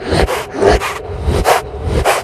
4aef571f59 Divergent / mods / Soundscape Overhaul / gamedata / sounds / monsters / psysucker / breath_0.ogg 19 KiB (Stored with Git LFS) Raw History
breath_0.ogg